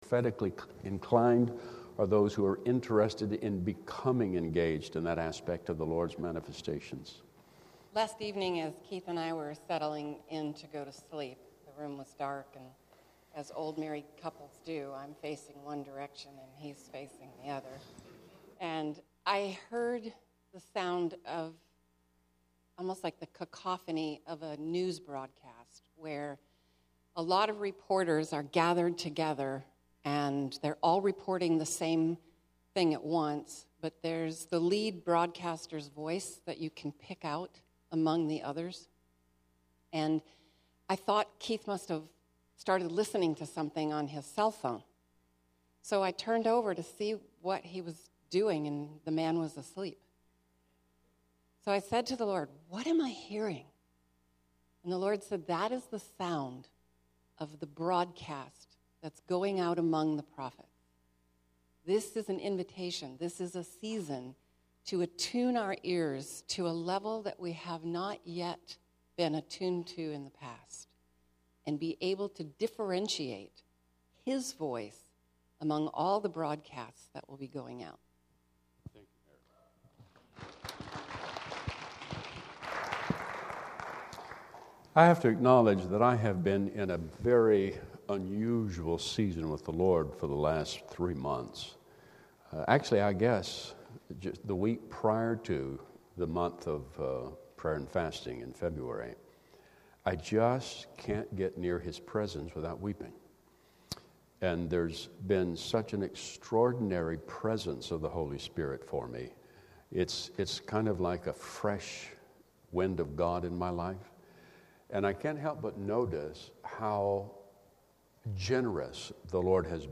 Encounter Service: Insights on Healing